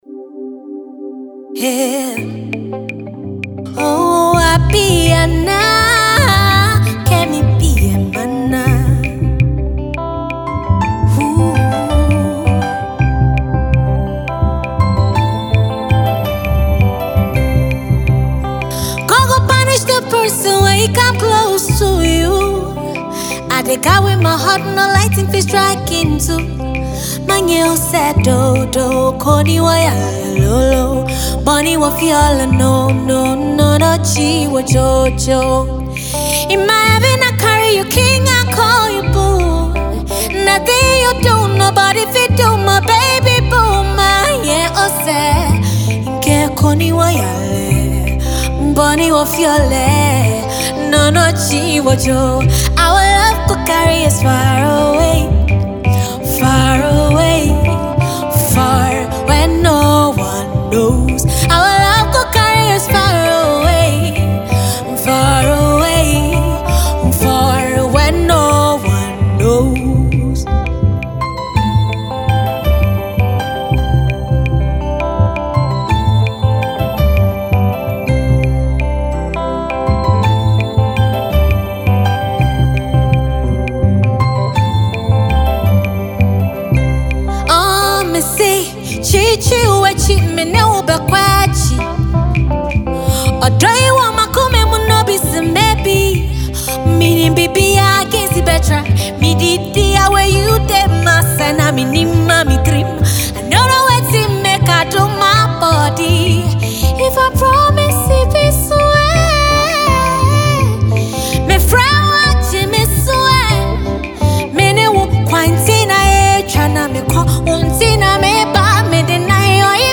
a Ghanaian songstress
acoustic production